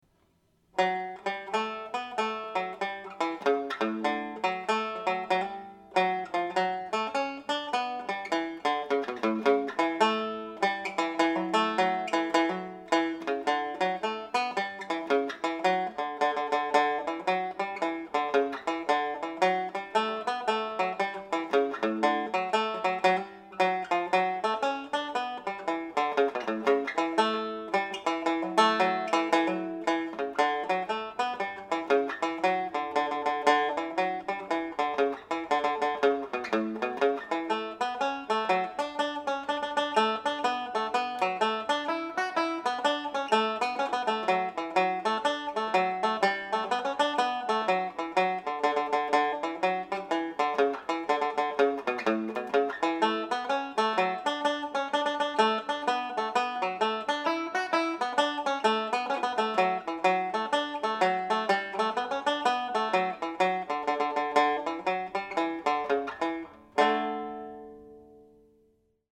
The notes high-lighted in green texted are played as a hammer-on. d3 is a triplet
Soporific hornpipe played slowly